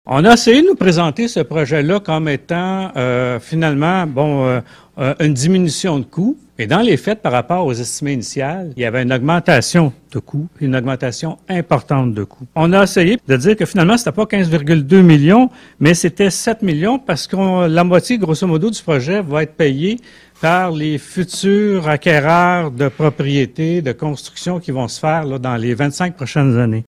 a toutefois manifesté son mécontentement quant à cette forme de taxation lors de la séance du conseil de Ville du 16 avril.